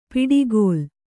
♪ piḍigōl